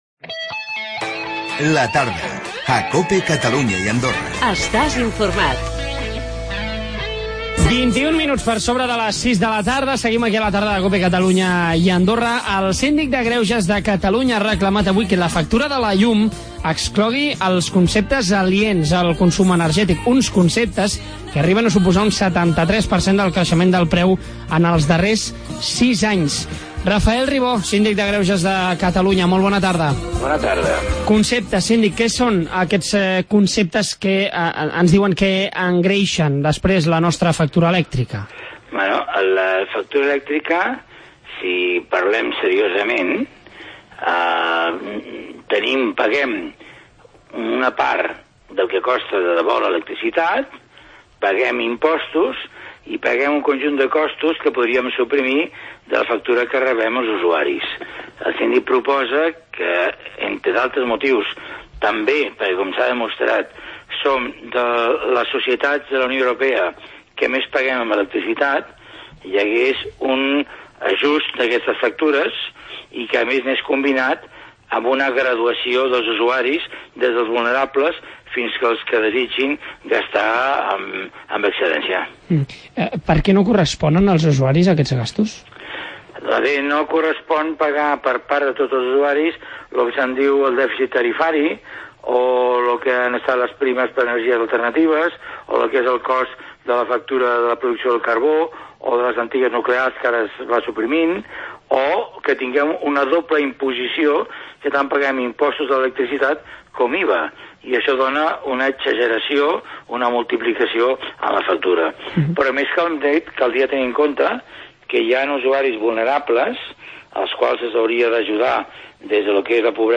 El Síndic de Greuges ha demanat que es treguin de la factura de la llum conceptes aliens a la factura. Ens ho ha explicat el Síndic, en Rafael Ribó